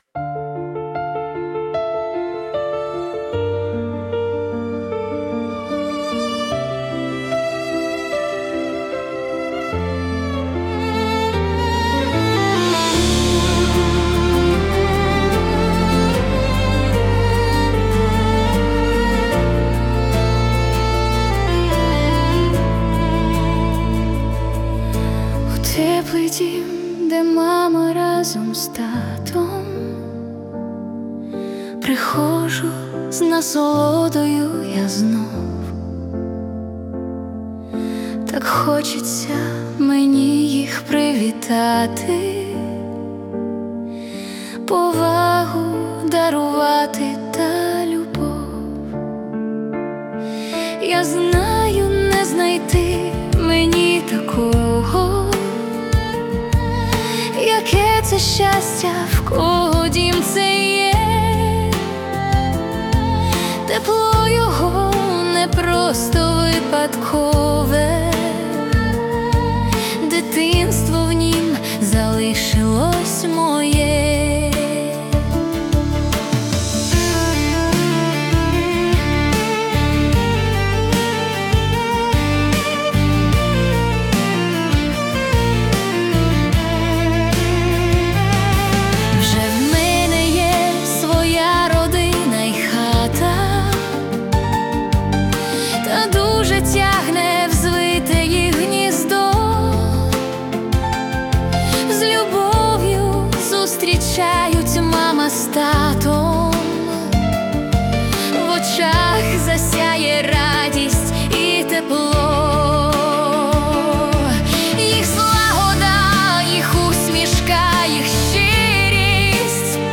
Pop Ballad / Nostalgia
музика, що дихає теплом і спокоєм (100 BPM)